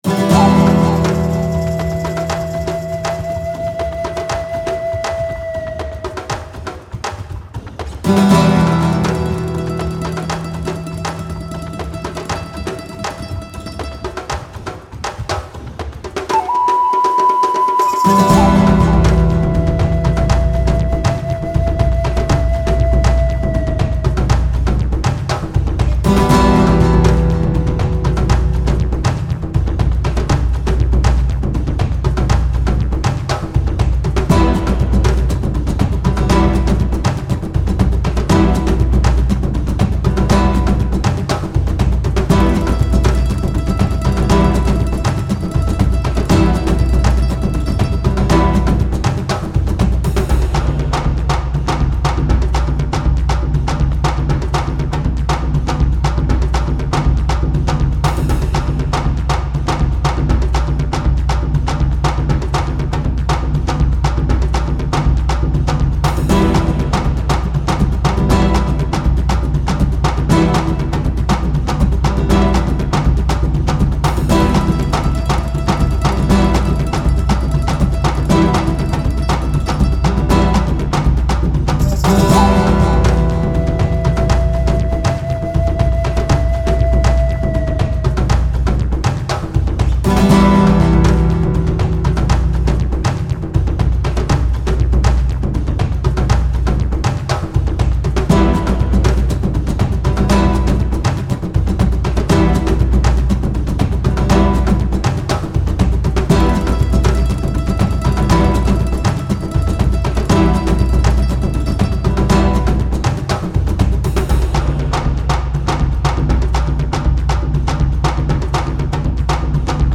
盛り上がる一歩手前で流すと良さそうな曲
アコースティック, シネマチック 3:31 ダウンロード